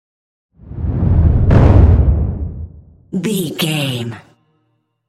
Dramatic deep whoosh to hit trailer
Sound Effects
Atonal
dark
intense
tension
woosh to hit